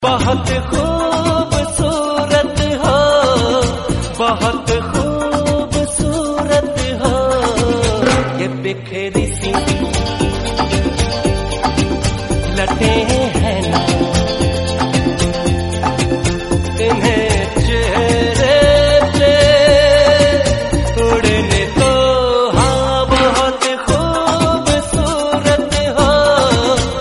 Romantic and heart-touching ringtone for mobile.